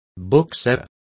Complete with pronunciation of the translation of booksellers.